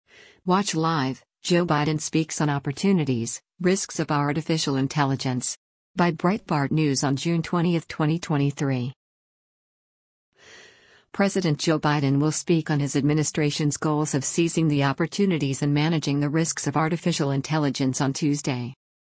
Watch Live: Joe Biden Speaks on Opportunities, Risks of Artificial Intelligence